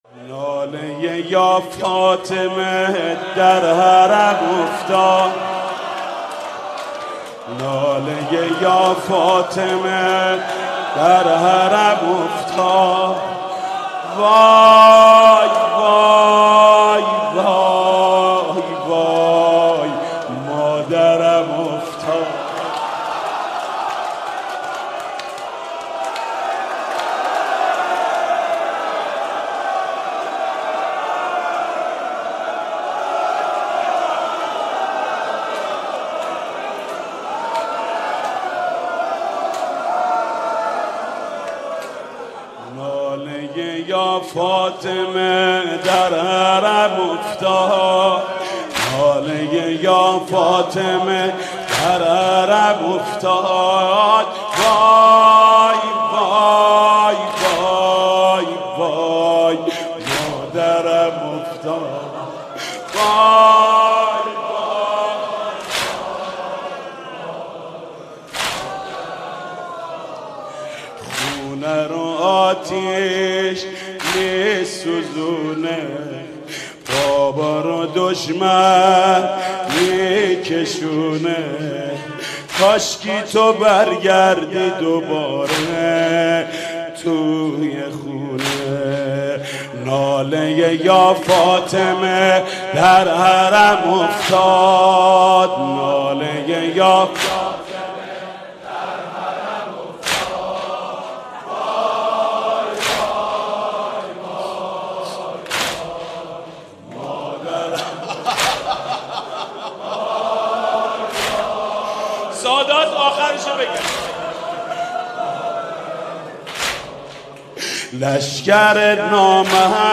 واحد سوزناک